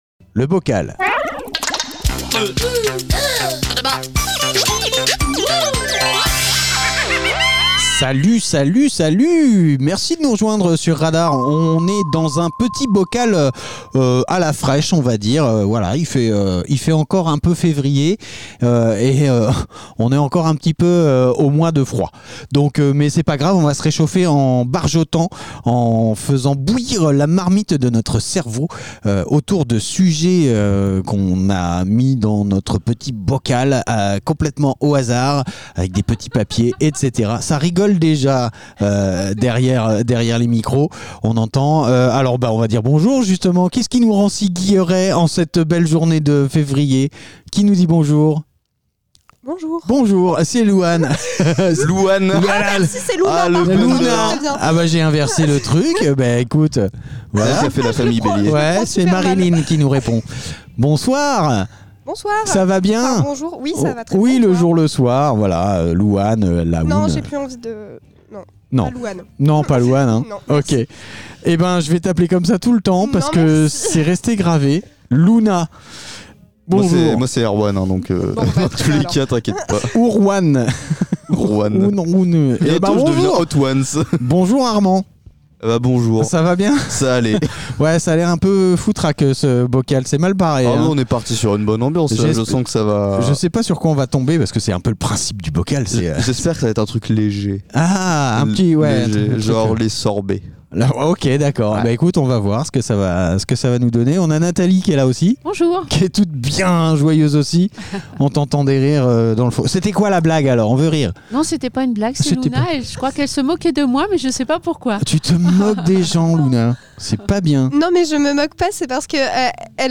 Une poignée d'invités propose des sujets de débats à bulletin secret.
Le sujet, une fois dévoilé, donne lieu à des conversations parfois profondes, parfois légères, toujours dans la bonne humeur !